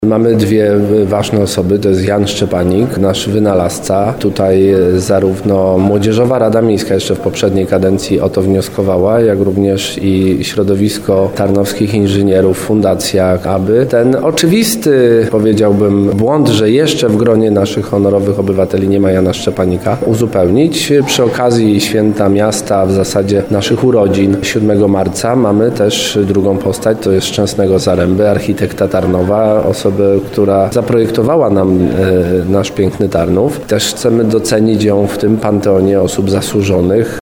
Też chcemy docenić ją w tym panteonie osób zasłużonych -mówił prezydent Tarnowa Jakub Kwaśny.